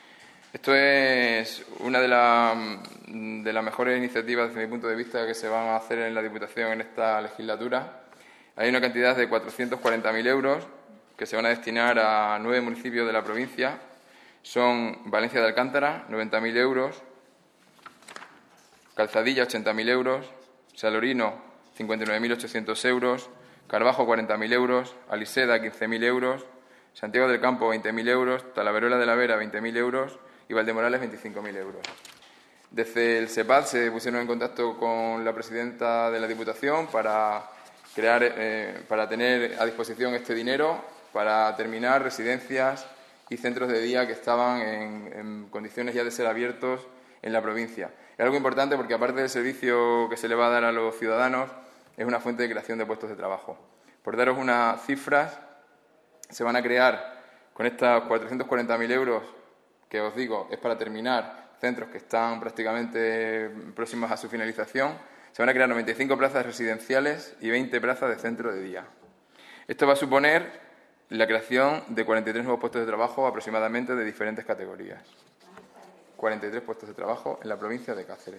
CORTES DE VOZ
Fernando_G._Nicolxsx_pleno_julio_2016.mp3